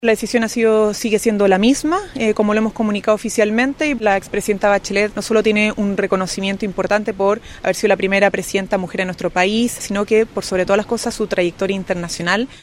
Consultada por La Radio, la ministra vocera de Gobierno, Camila Vallejo, ratificó la decisión del presidente Gabriel Boric de inscribir oficialmente la candidatura de Michelle Bachelet, a pesar que su respaldo para seguir en carrera dependerá de la futura administración de José Antonio Kast.